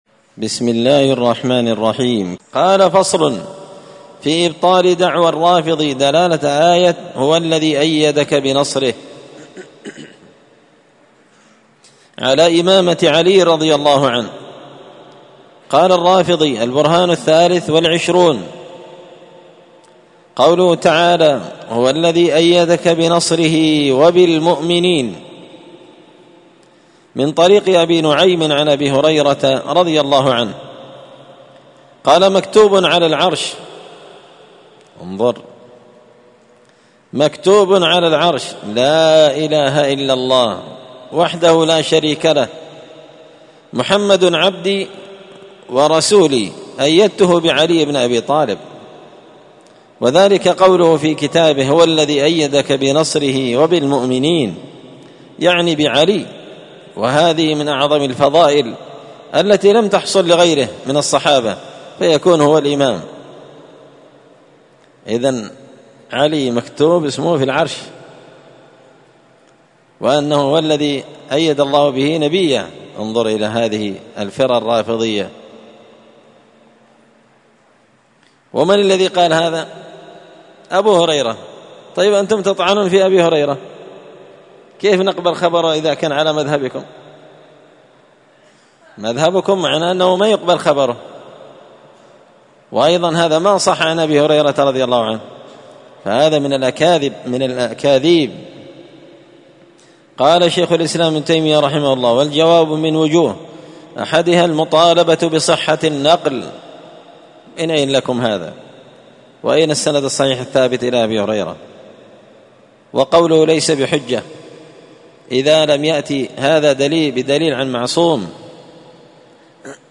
الأثنين 12 صفر 1445 هــــ | الدروس، دروس الردود، مختصر منهاج السنة النبوية لشيخ الإسلام ابن تيمية | شارك بتعليقك | 102 المشاهدات
مسجد الفرقان قشن_المهرة_اليمن